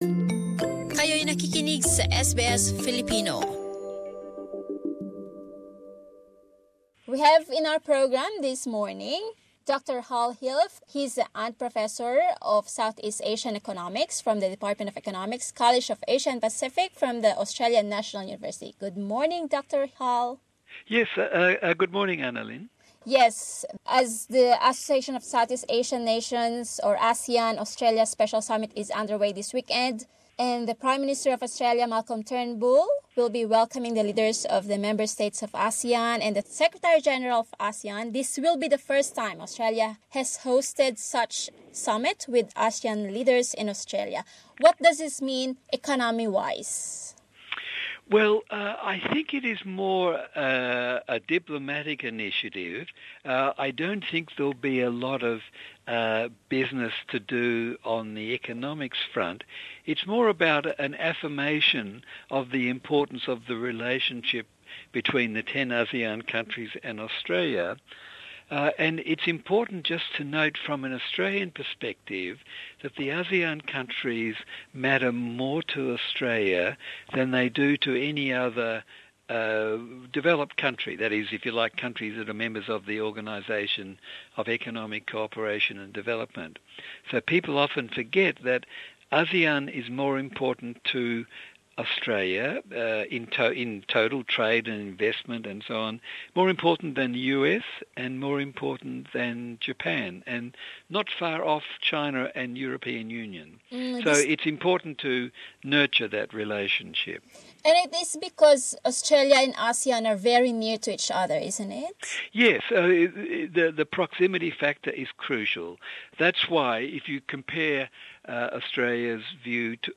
As Australia hosts the ASEAN-Australia Special Summit this weekend in Sydney, we ask an academic why such summit is beneficial to member-states and Australia?